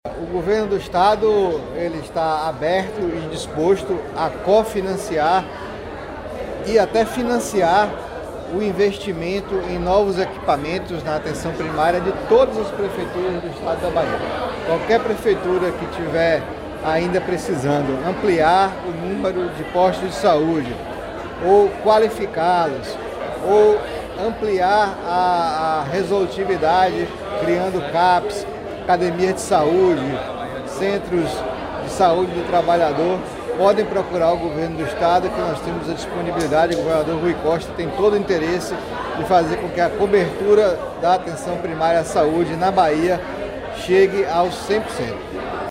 Durante a solenidade de inauguração do Centro de Atenção Psicossocial (CAPS) de Armação, na manhã de hoje, terça-feira, 17 de dezembro, em Salvador, o secretário da Saúde do Estado, Fábio Vilas-Boas, disse que o governo do Estado está “aberto e disposto a cofinanciar e até financiar o investimento em novos equipamentos na atenção primária de todas as prefeituras do estado da Bahia”.